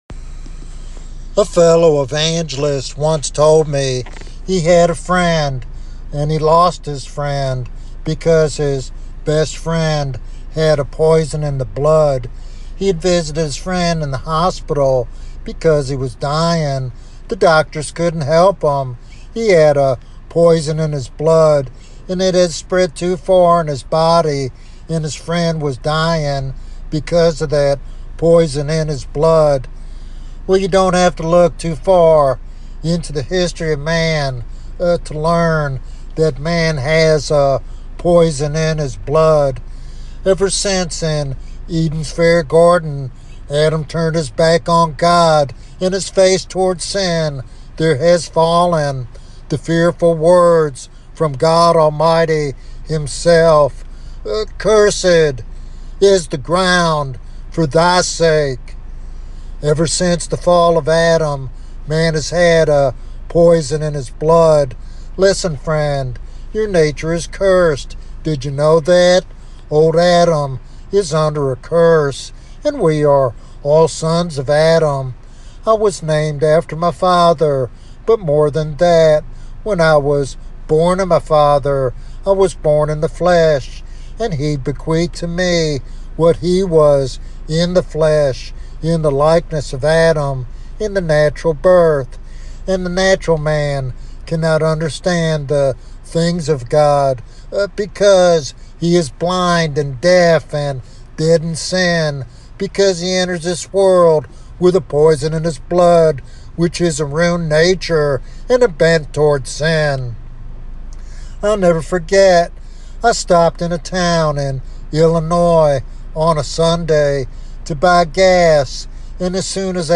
This evangelistic sermon calls listeners to repentance and faith in Christ for salvation and renewal.